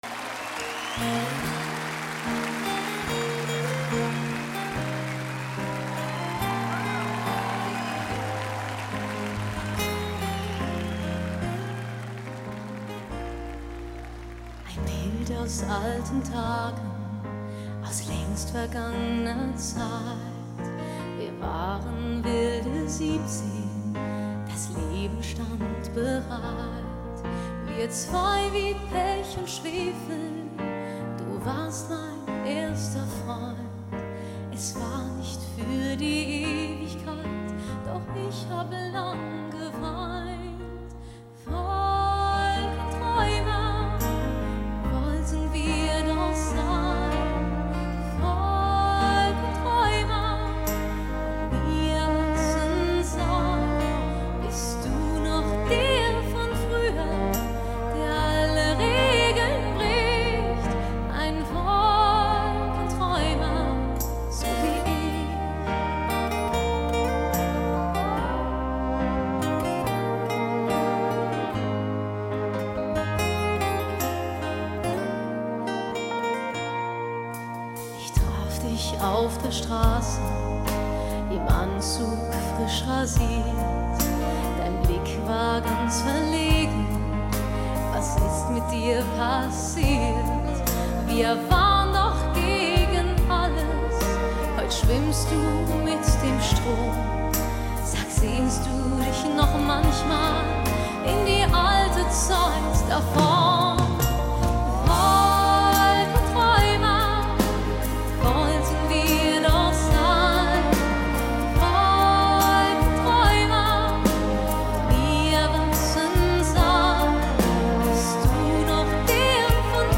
Genre: Pop, Schlager